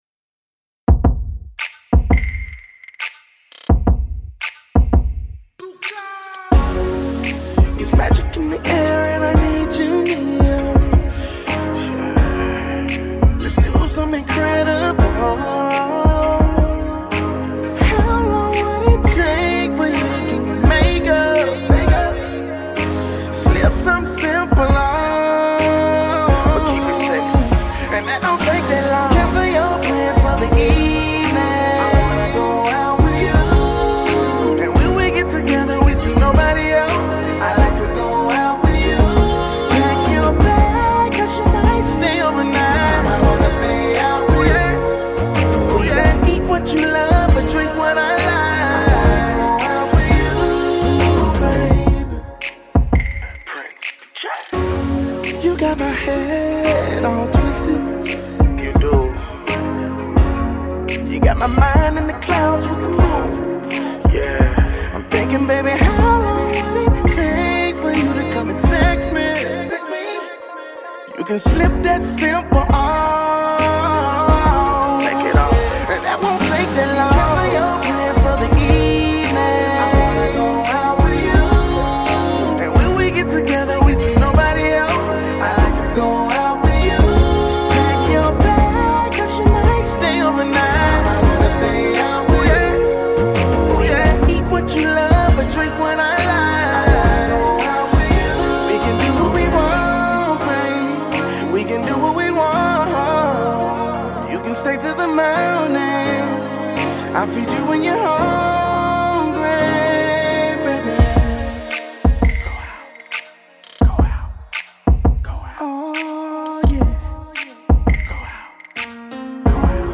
Hiphop
Description: An old school feel with a new school vibe.